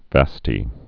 (văstē)